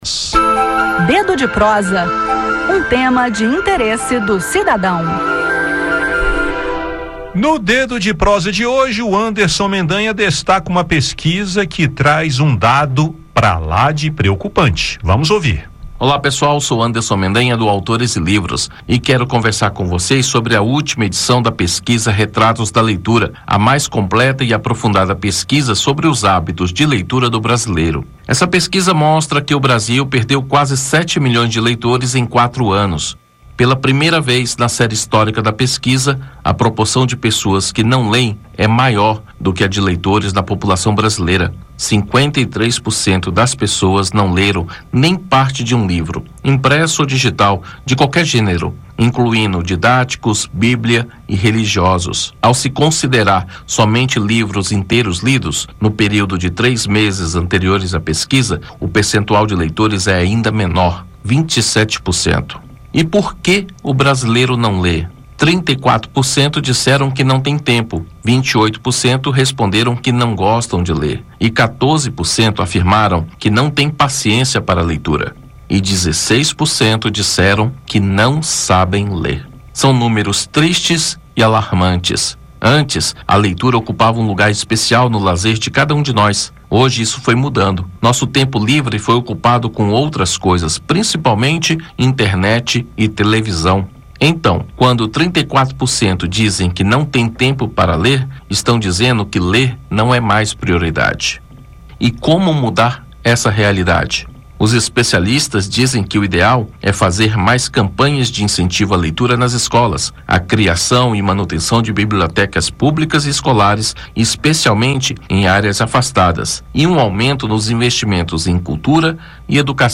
Diante disso, como podemos incentivar as pessoas a lerem? É o que você vai ouvir no bate-papo.